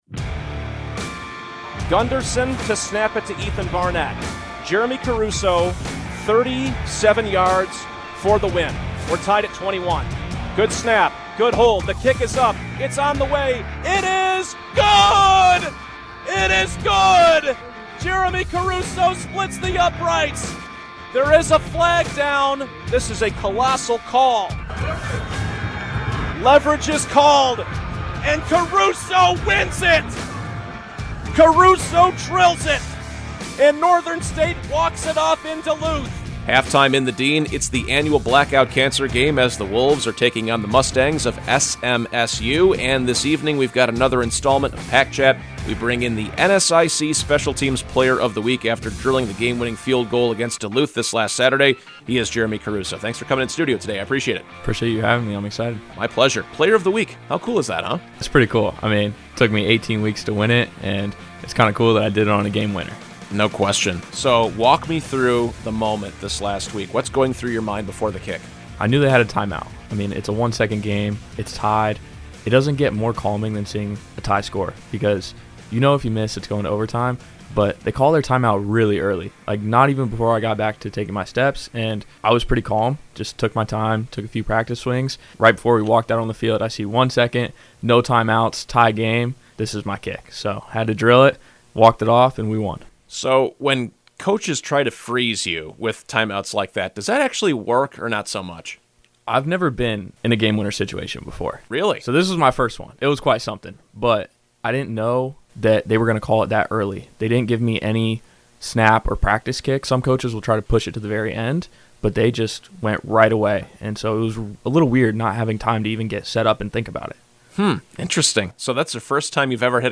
The interview also includes previously unheard audio!